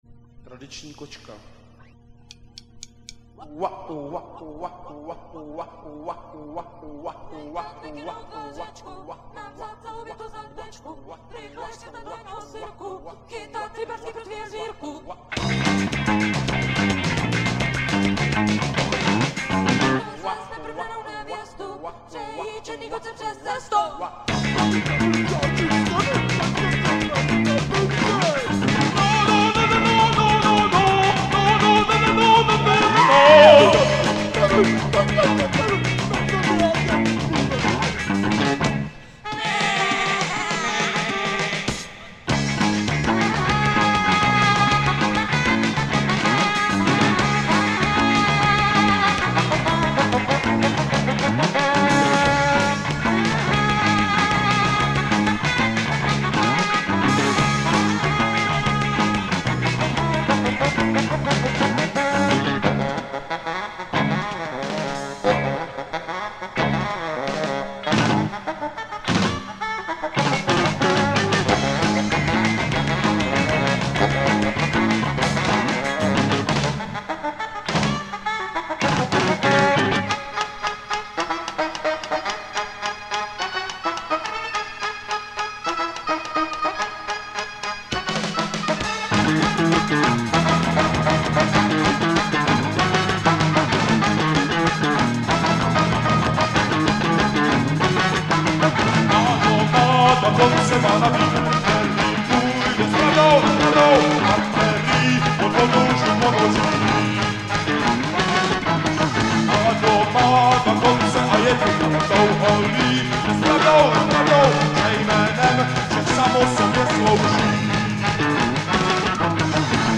je česká avantgardní rocková kapela.